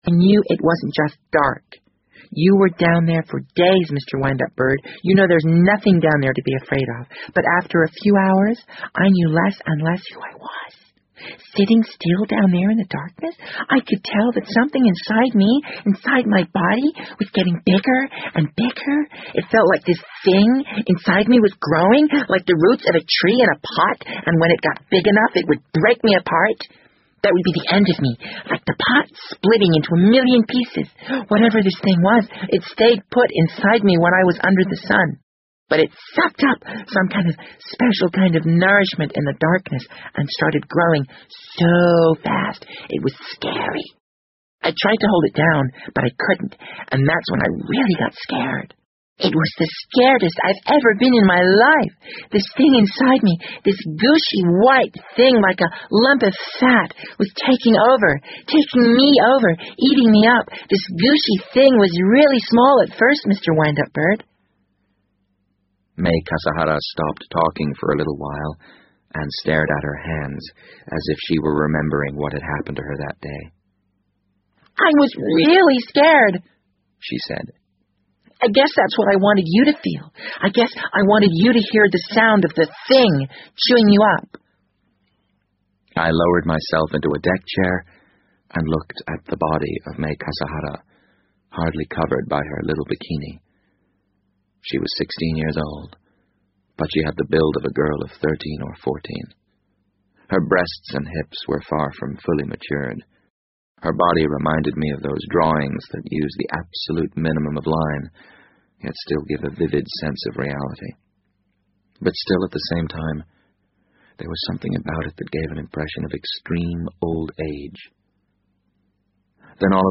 BBC英文广播剧在线听 The Wind Up Bird 008 - 18 听力文件下载—在线英语听力室